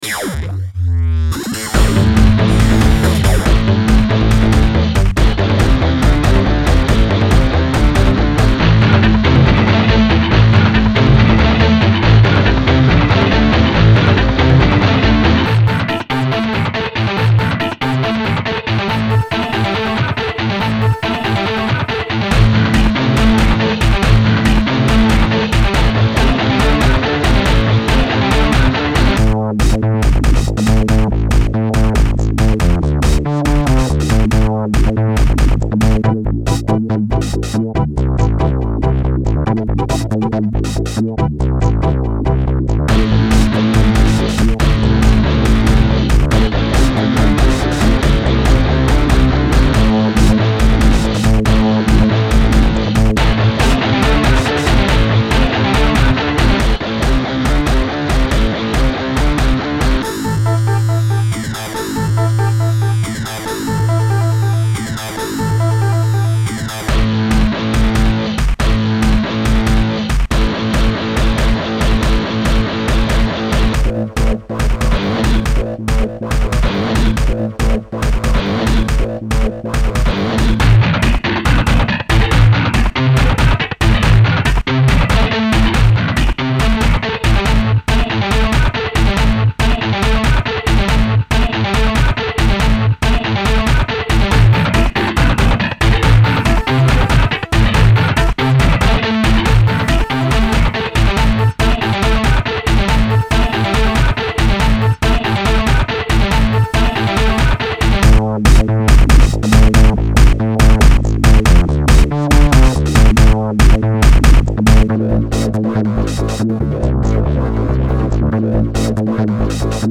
Experimental track trying to mix some trance concepts with some dirty guitar and 90's techno feel. This track was made for an action scene at a post-retro nightclub, and probably would fit in for urban/tech scenes.